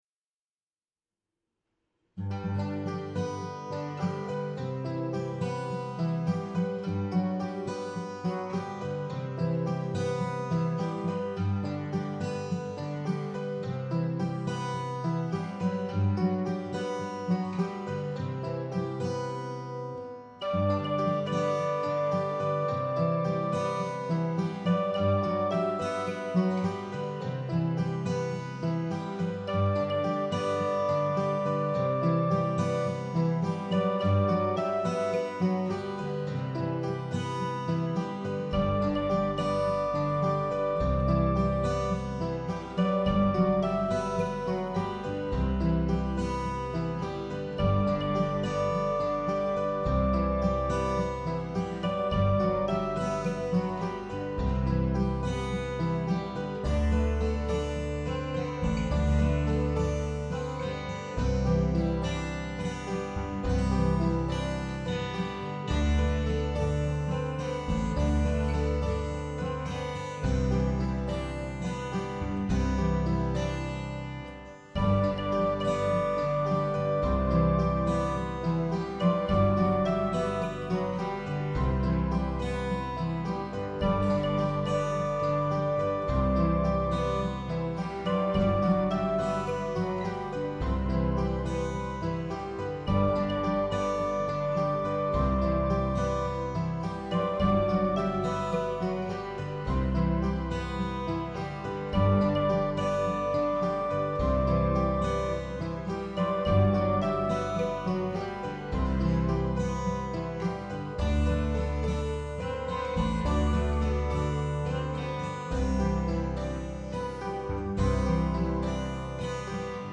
Note that the acoustic guitar parts were recorded in my room.
Also note ... this recording is a very rough mix that requires lots of work before I feel good